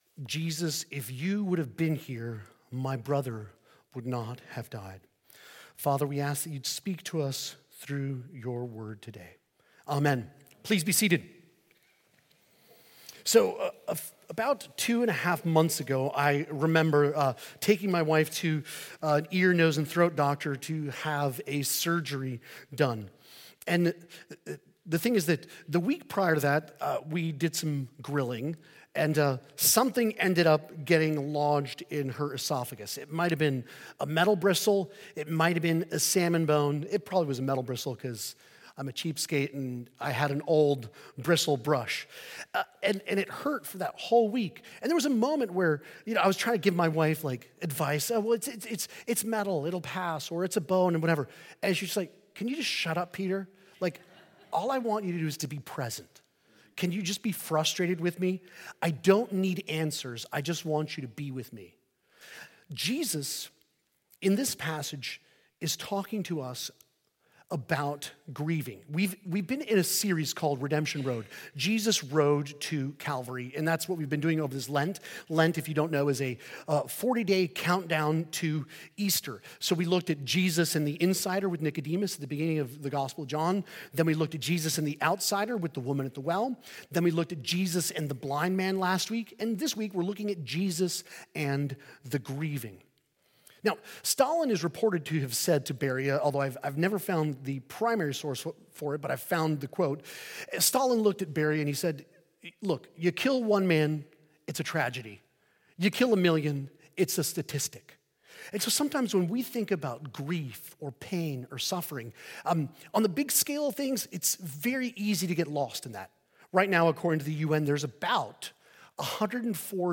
This sermon explores how Jesus meets people in their grief through tears, truth, and grace, drawing from the story of Lazarus in John 11. It highlights how Jesus responds differently to Mary and Martha—offering compassionate presence to one and grounding truth to the other—showing his intimate understanding of human sorrow. Jesus points to the hope of resurrection, reminding listeners that he enters death itself so that everything sad might one day come untrue.